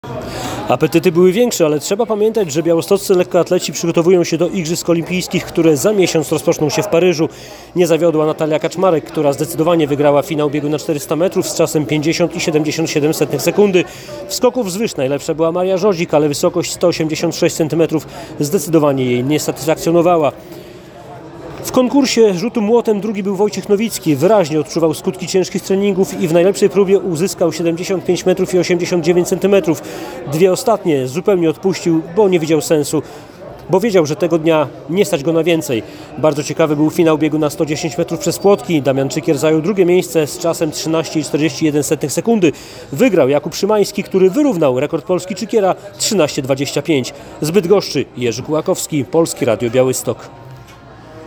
Mistrzostwa Polski w lekkiej atletyce - relacja